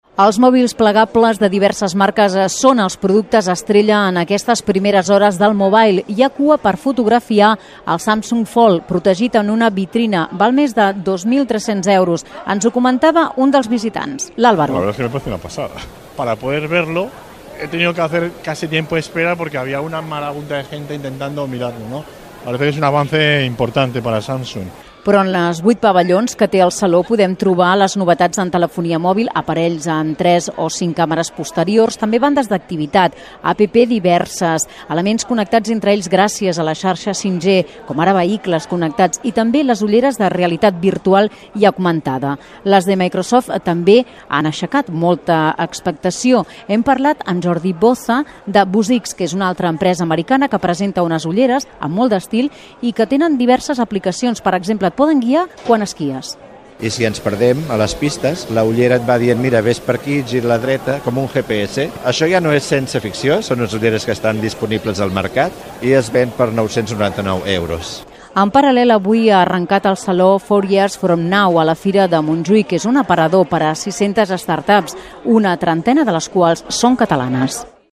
Informatiu migdia: MWC - Catalunya Ràdio. 2019